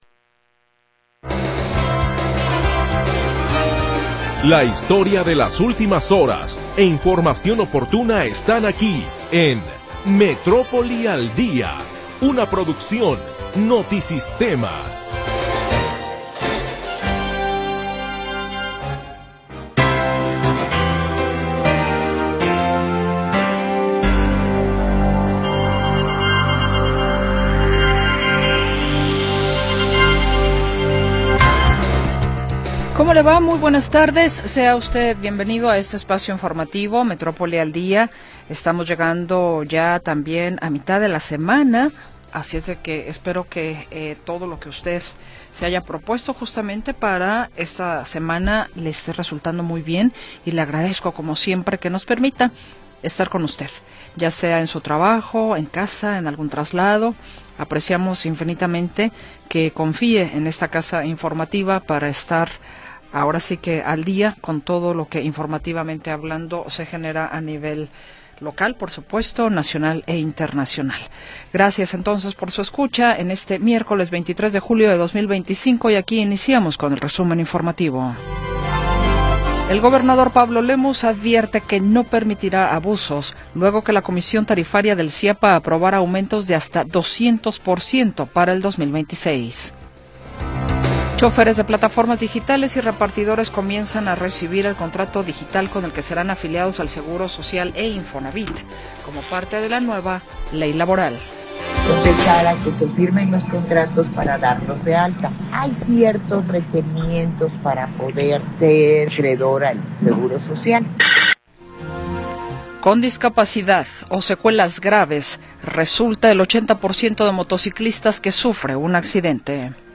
Primera hora del programa transmitido el 23 de Julio de 2025.